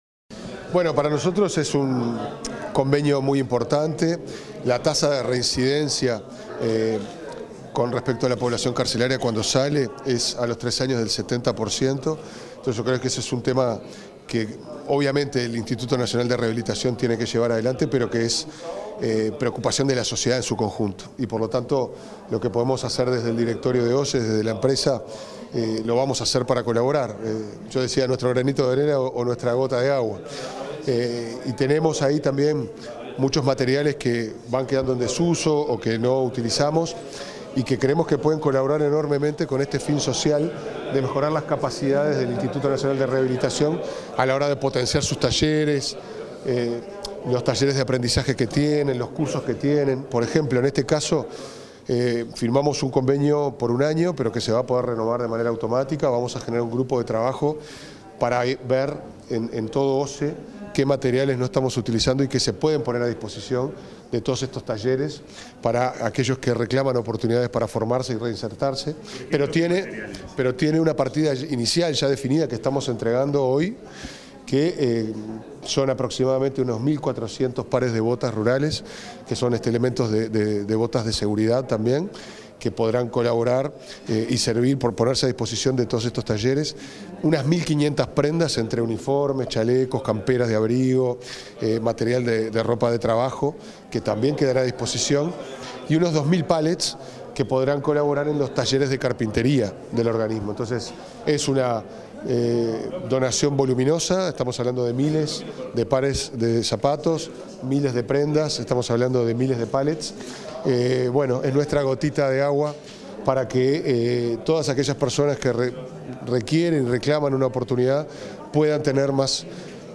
Declaraciones del presidente de OSE, Pablo Ferreri
Declaraciones del presidente de OSE, Pablo Ferreri 05/11/2025 Compartir Facebook X Copiar enlace WhatsApp LinkedIn En la firma del convenio para la donación de insumos entre OSE y el Instituto Nacional de Rehabilitación, el presidente de la empresa estatal, Pablo Ferreri, diálogo con los medios de prensa.